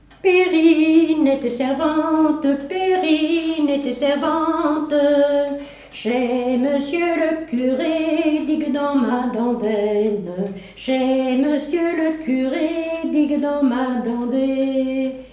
Localisation Saint-Martin-Lars
Pièce musicale inédite